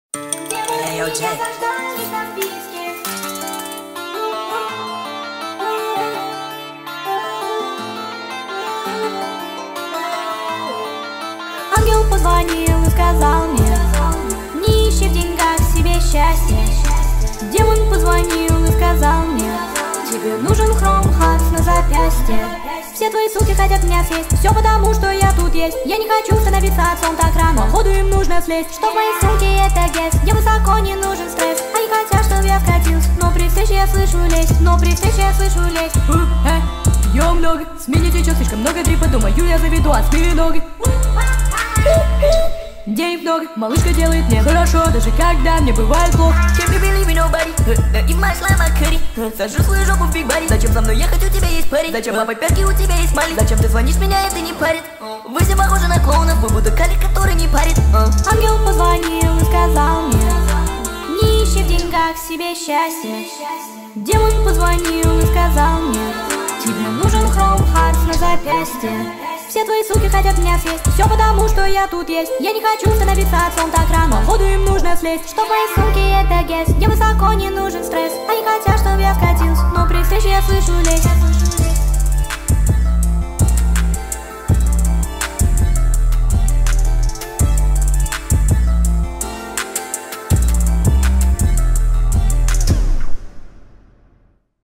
тик ток ремикс